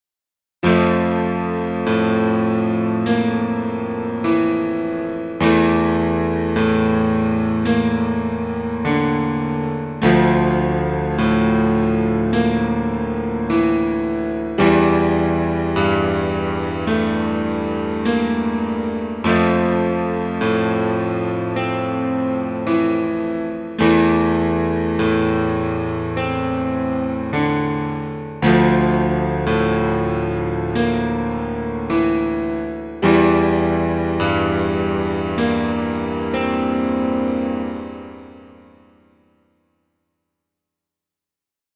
piano shite
not that great quality. not a full song in the group.
said_piano_140.mp3